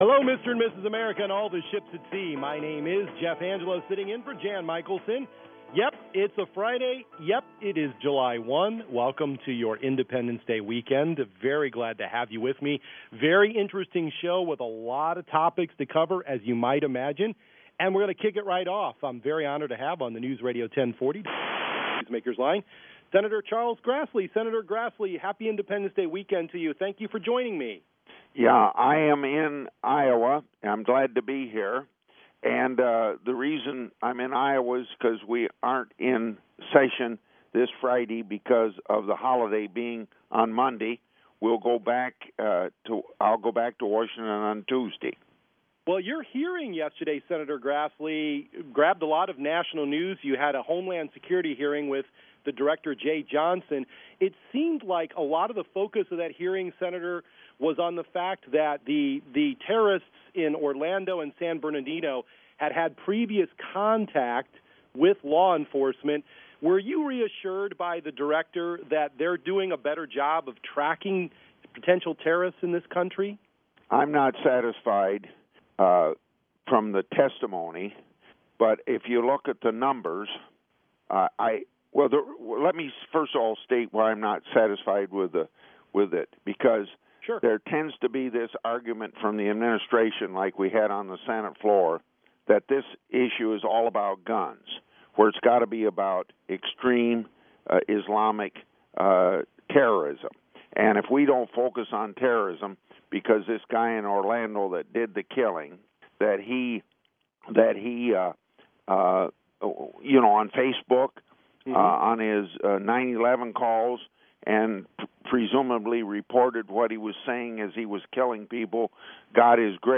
Grassley Live on WHO radio
Public Affairs Program, 7-1-16, WHO radio.mp3